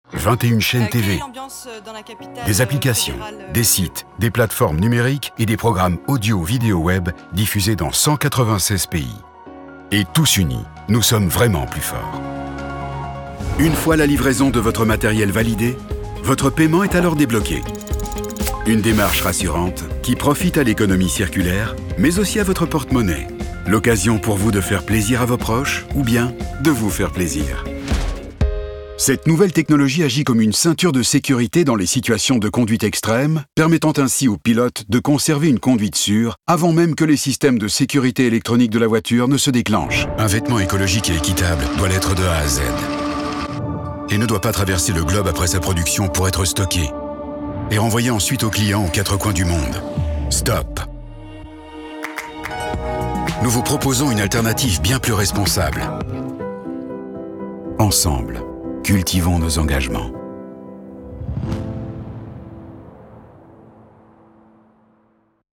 With 30 years of experience forged by numerous projects around the world, I can help you enhance your message or your brand with a warm, reassuring and, above all, a male voice that speaks to you.
Sprechprobe: Industrie (Muttersprache):